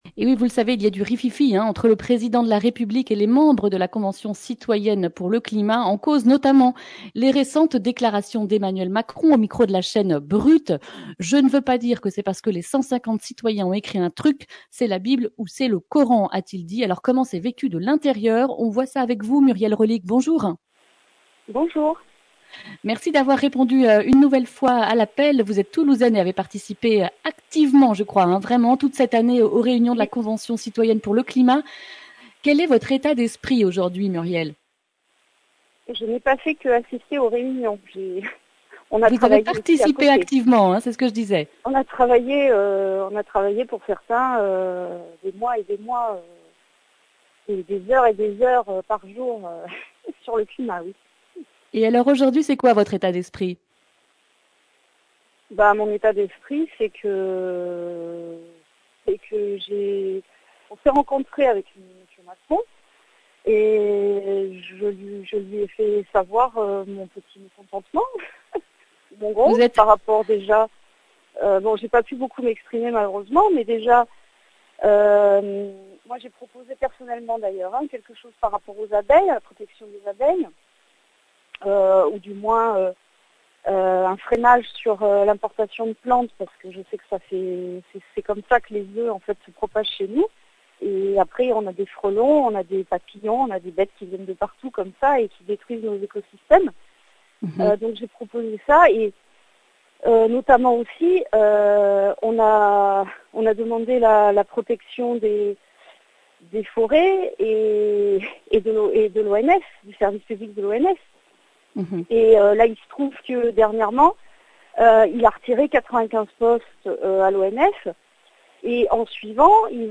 mercredi 16 décembre 2020 Le grand entretien Durée 10 min
Une émission présentée par